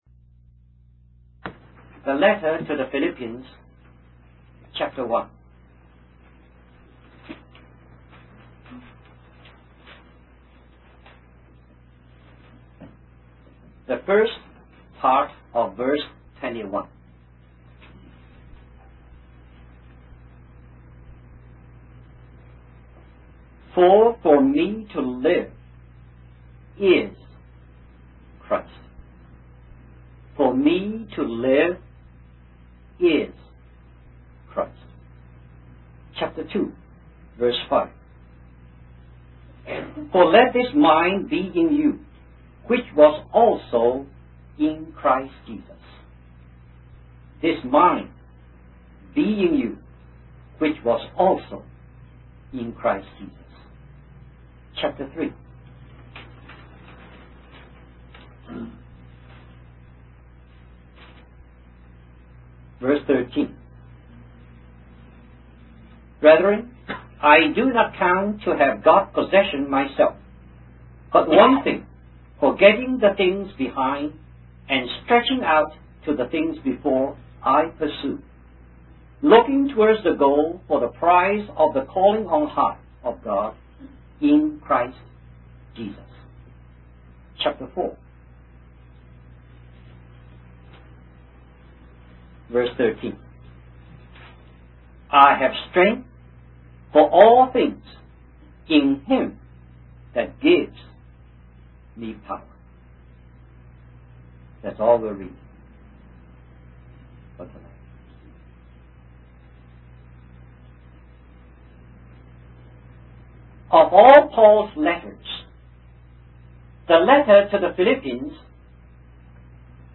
In this sermon, the preacher focuses on the book of Romans, specifically chapters 1 to 11, which highlight what God has done for us in Christ.